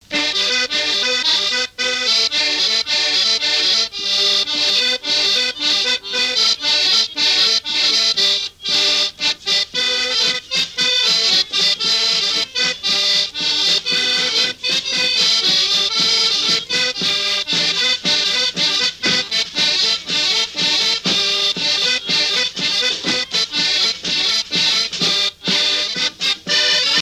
Chants brefs - A danser
Fonction d'après l'analyste danse : gigouillette
Pièce musicale inédite